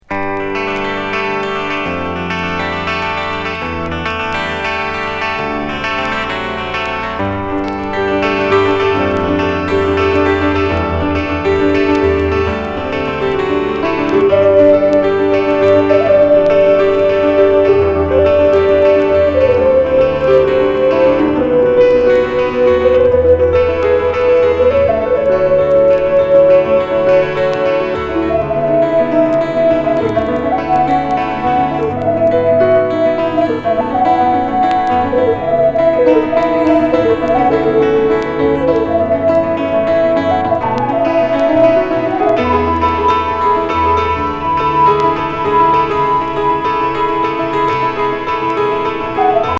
ボストン録音79年作。